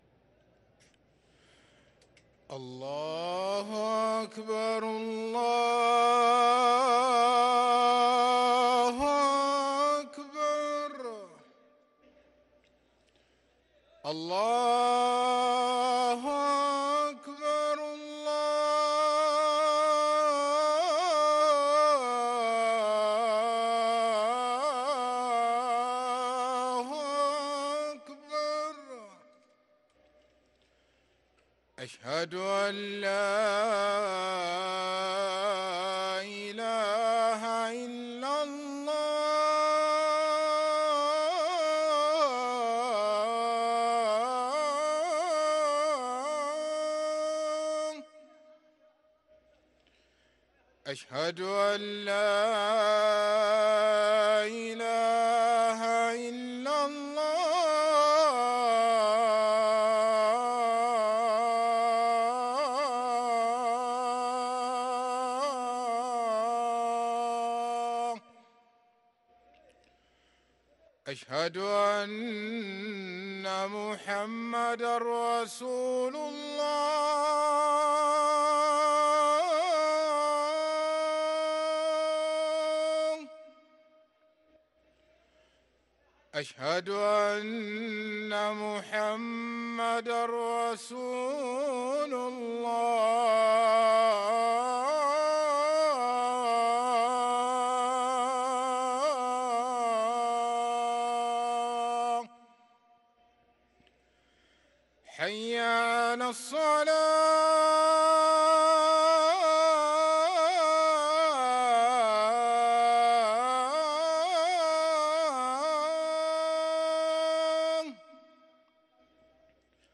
أذان المغرب للمؤذن سعيد فلاته الجمعة 8 جمادى الأولى 1444هـ > ١٤٤٤ 🕋 > ركن الأذان 🕋 > المزيد - تلاوات الحرمين